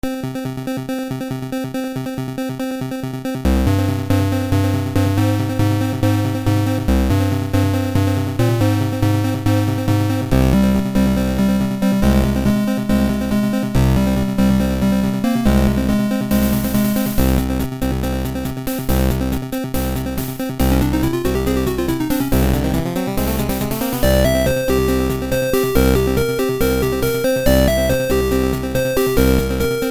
Cave theme